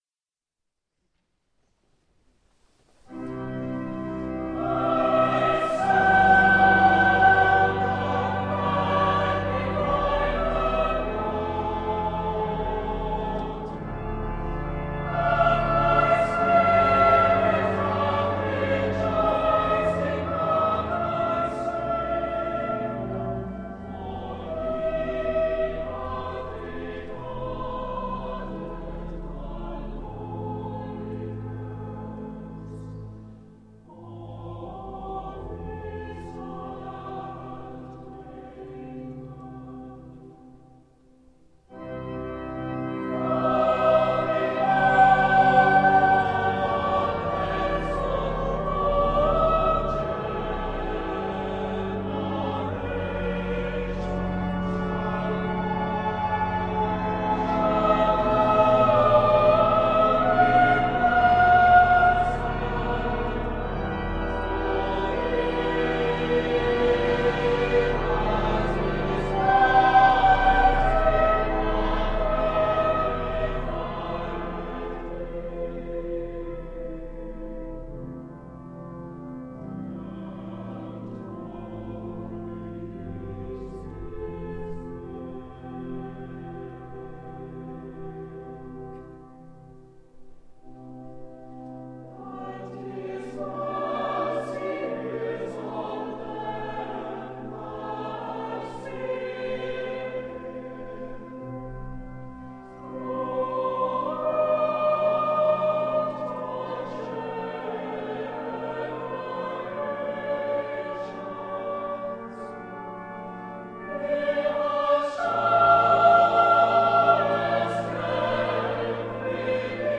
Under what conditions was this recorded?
For those recordings made during live services, the permission of the appropriate authority was sought and obtained.